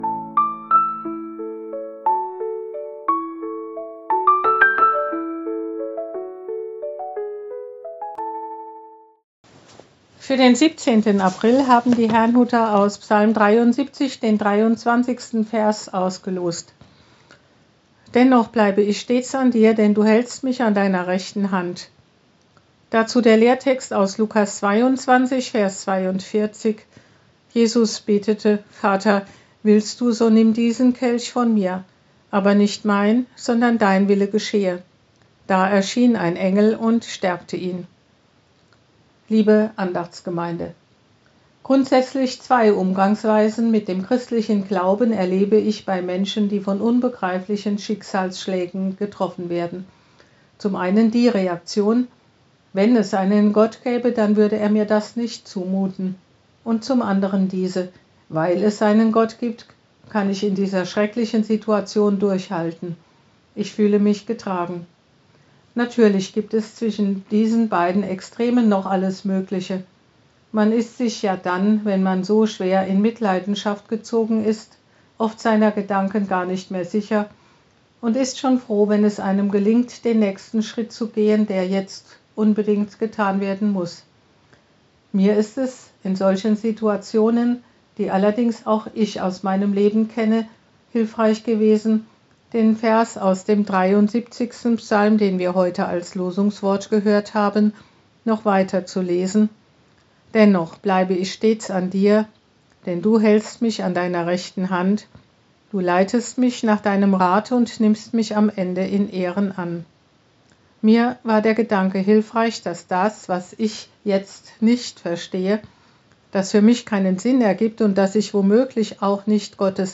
Losungsandacht für Donnerstag, 17.04.2025